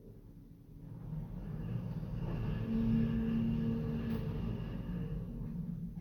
Lift moving 3.mp3